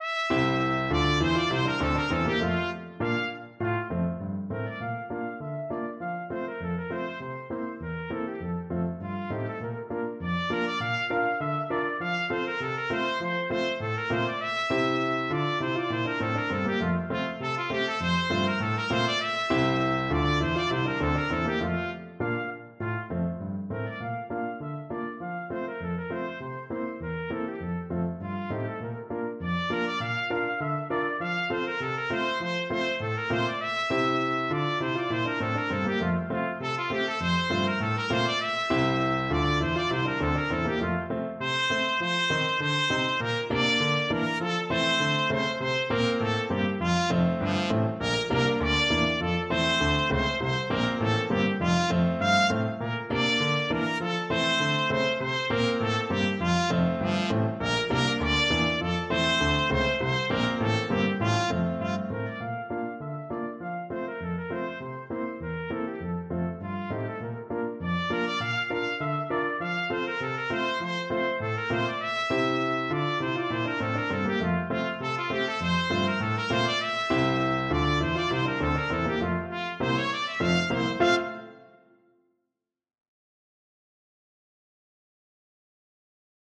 2/4 (View more 2/4 Music)
F4-F6
Traditional (View more Traditional Trumpet Music)
Finnish